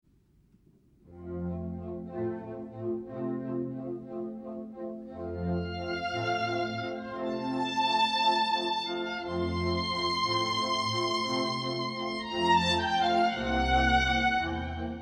Classical, Instrumental, Piano